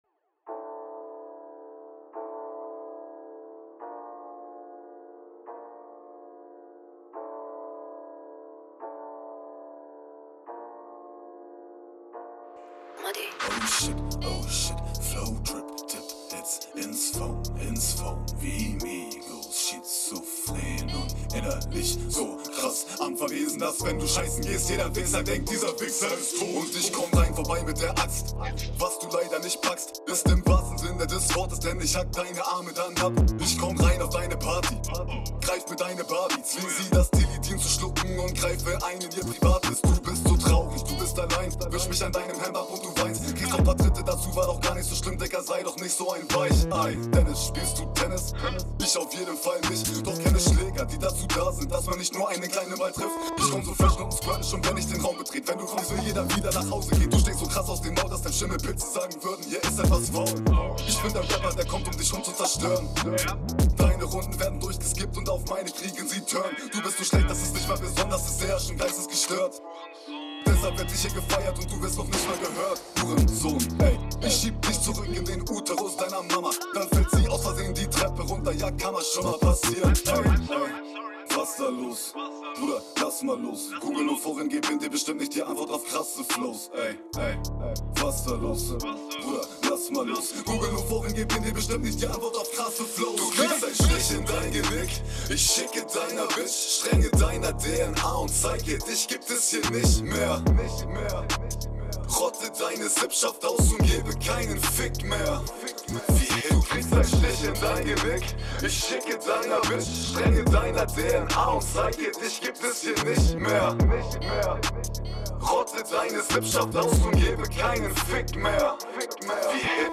Cooler Beat, passt wieder astrein zu deinem düsteren Stimmeinsatz!